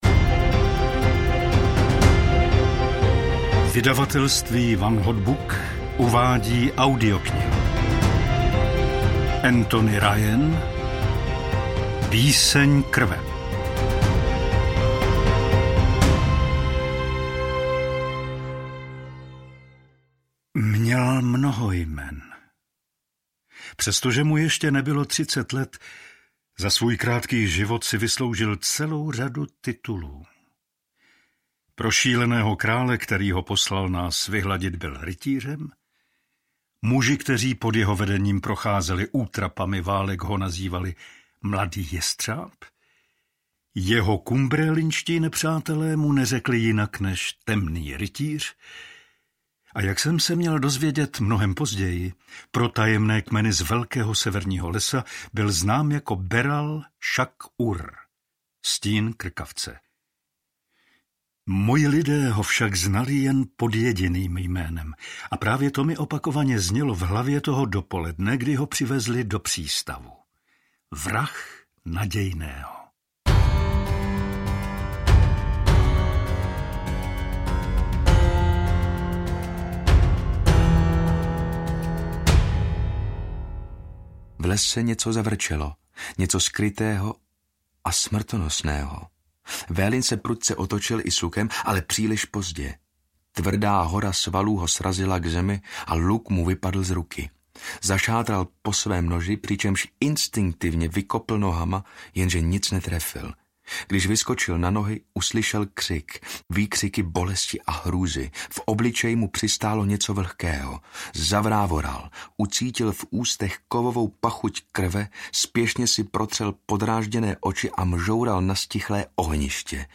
Píseň krve audiokniha
Ukázka z knihy
pisen-krve-audiokniha